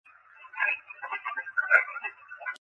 On June 12, I was recording a short session with the DR-60. I asked the spirits what they thought of me posting my videos on my Youtube channel.
Here is just the response (enhanced).